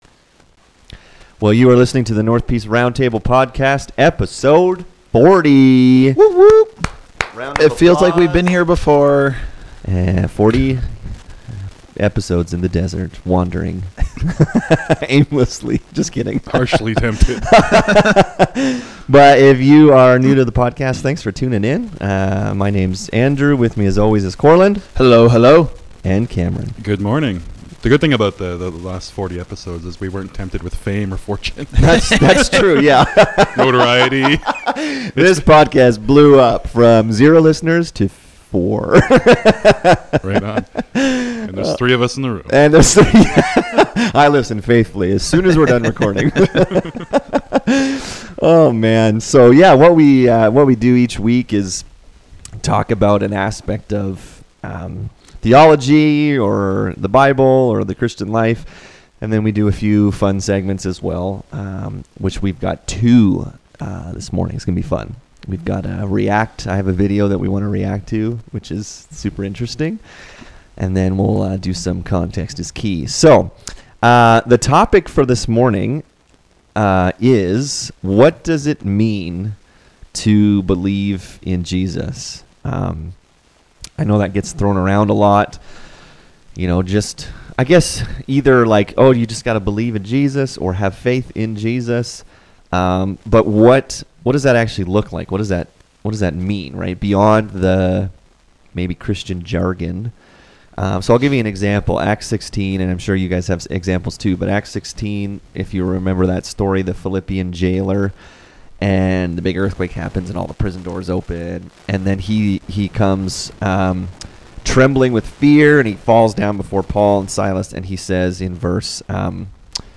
in this episode the guys talk about believing in Jesus. What does that actually mean?